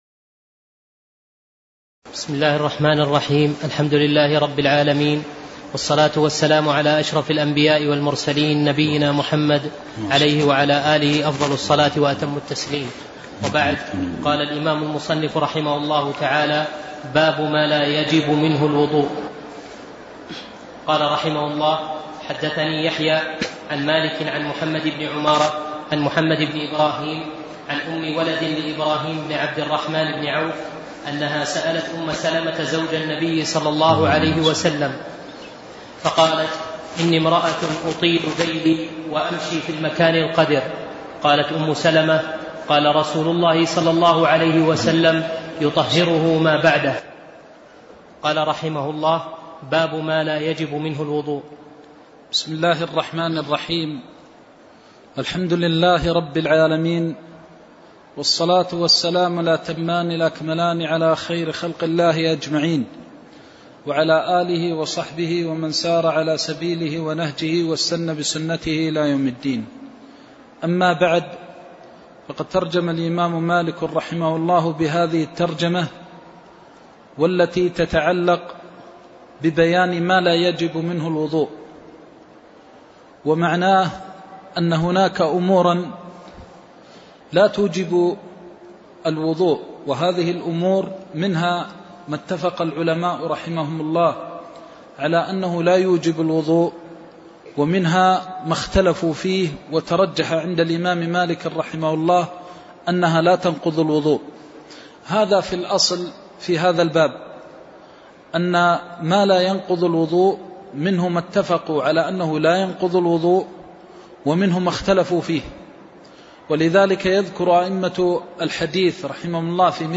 الدرس العاشر من قول المصنف رحمه الله :باب ما لا يجب منه الوضوء إلى قول المصنف رحمه الله :باب جامع الوضوء